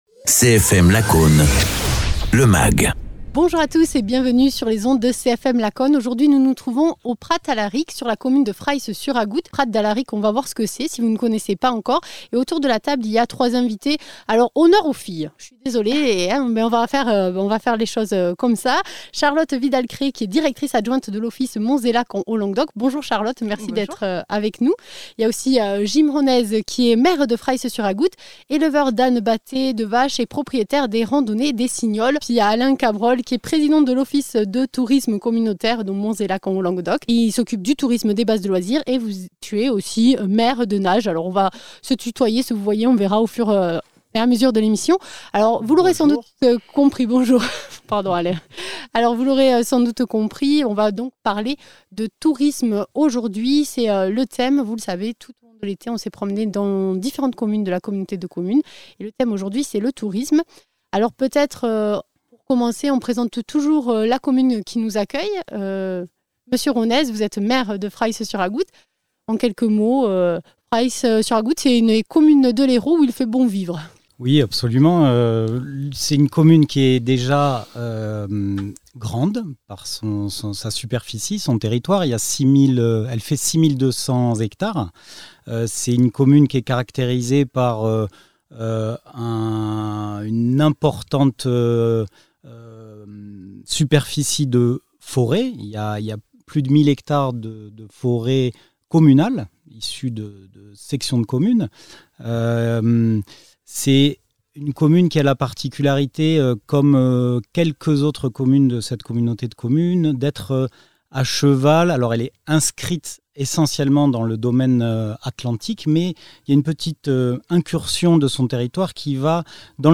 Cette semaine, nous avons pris la direction de Fraïsse-sur-Agoût (Hérault) pour poser notre studio mobile au Prat d’Alaric. Un lieu riche tant au niveau patrimonial que naturel; le lieu idéal pour parler du tourisme sur la destination Monts et Lacs en Haut Languedoc.
Interviews